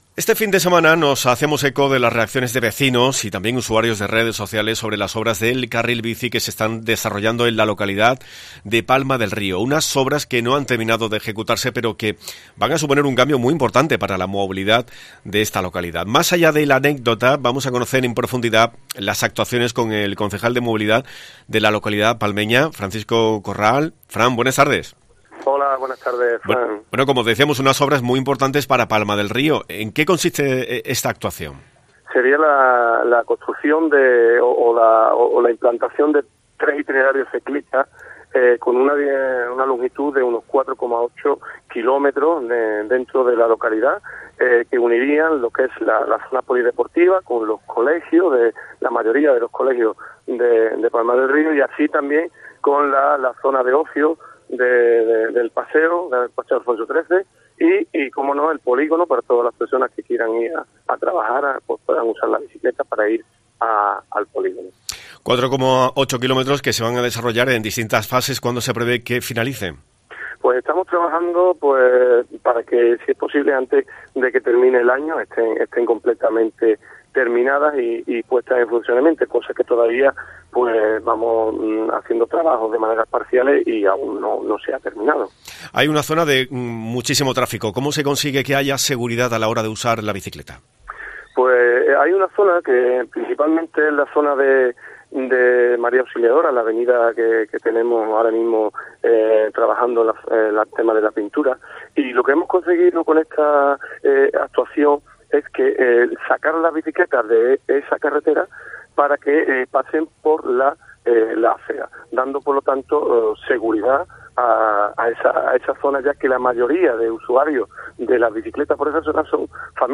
Hablamos con Francisco Corral, concejal de Seguridad y Movilidad, que nos ha explicado las actuaciones cuyo objetivo es "hacer referente a la localidad en movilidad sana y segura"